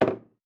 Ball Sink Distant.wav